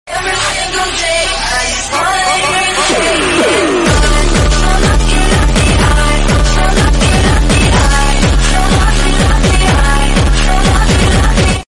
Super Slowed